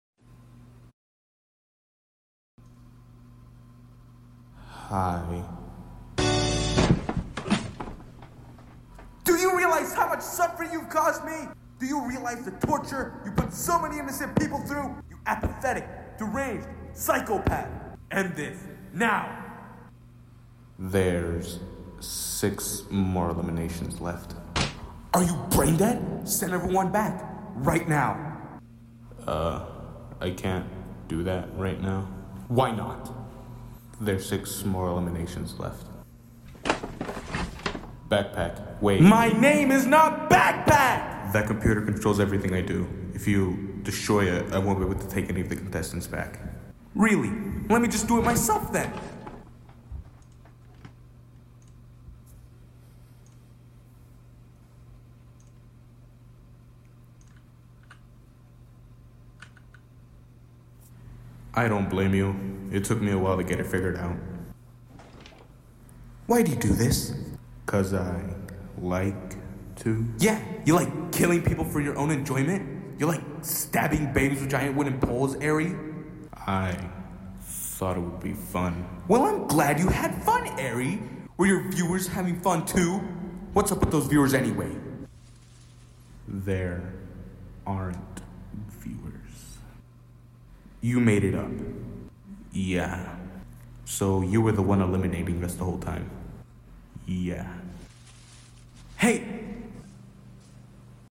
I remade an old voiceover sound effects free download
I did, I would have yelled louder but neighbors and everything so I couldn’t, I only made this cause I was home alone and finally had a chance to yell